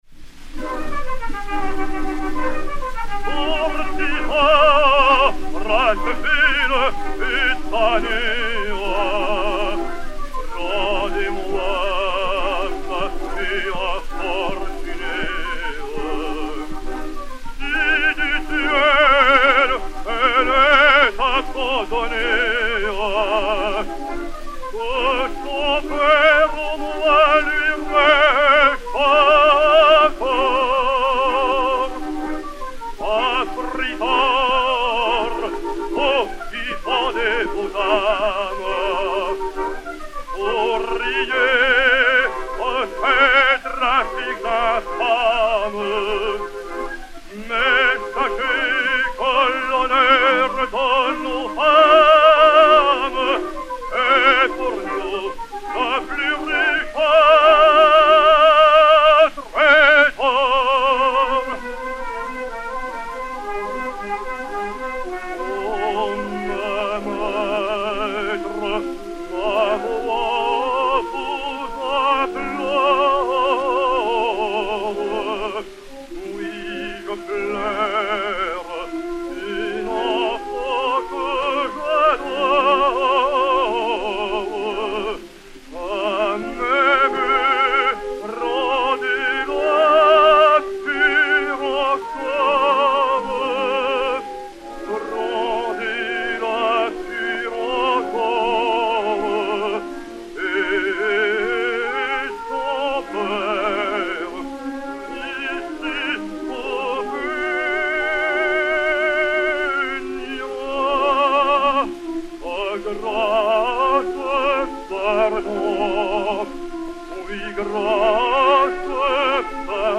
baryton français
Henri Dangès (Alphonse XI) et Orchestre
Pathé saphir 90 tours n° 4940 réédité sur 80 tours n° 137, enr. en 1908